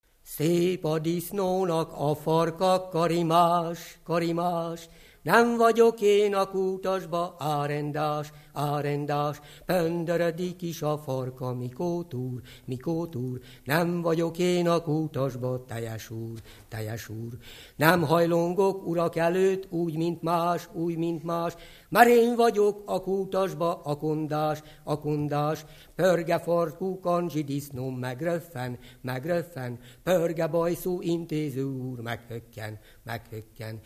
Alföld - Bács-Bodrog vm. - Bátmonostor
Stílus: 4. Sirató stílusú dallamok